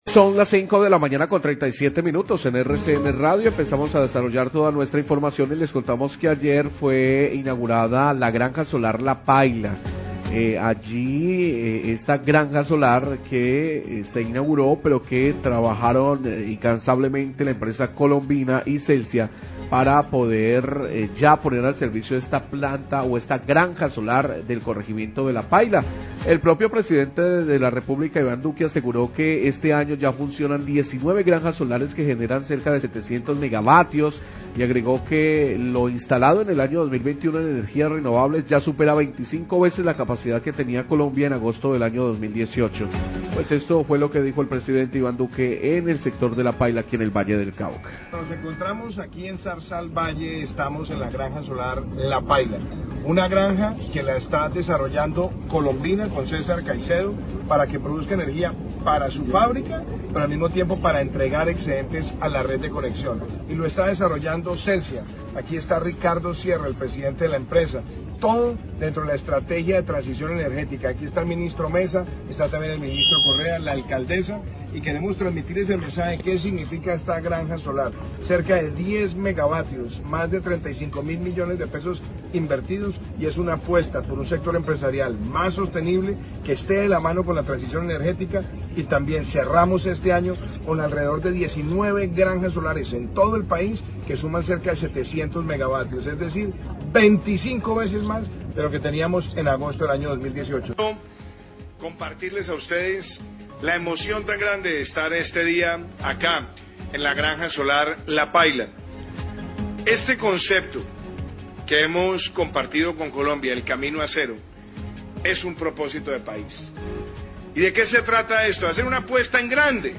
Declaraciones del Pdte Duque y Minminas durante inauguración granja solar Celsia en La Paila
Radio